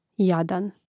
Rastavljanje na slogove: ja-dan